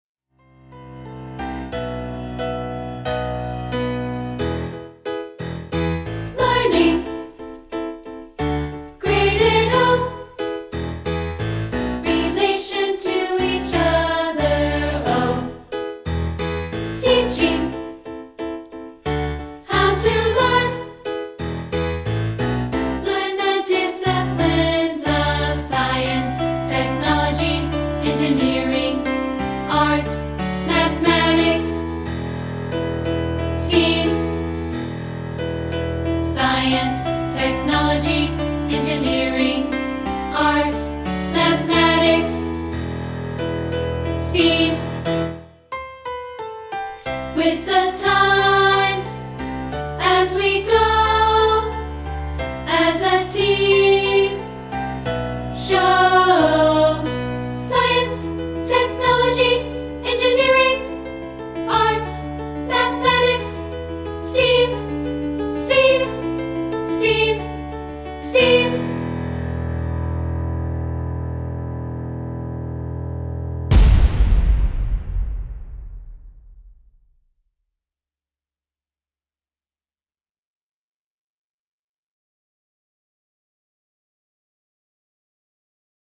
We've isolated part 2 as a rehearsal track